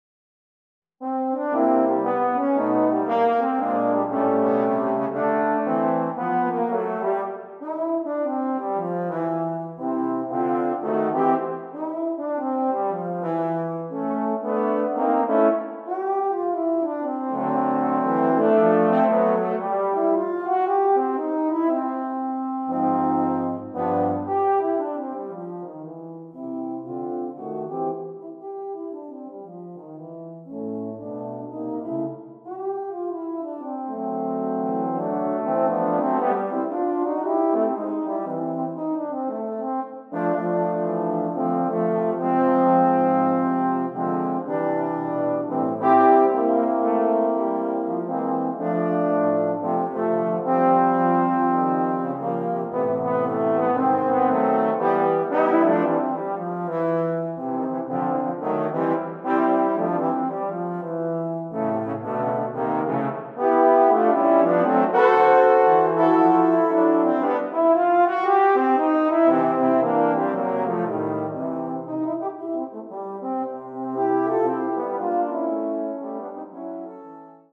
Instrument: flexible brass in 4 parts inc score - Euphoniums
for four Euphoniums.